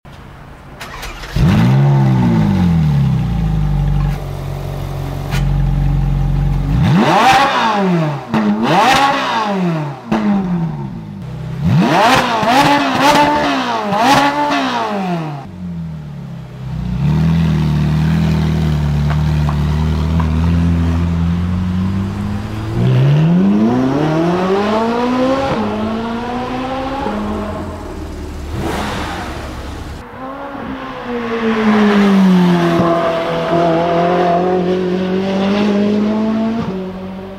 ENGINE SIZE 4.5 L V8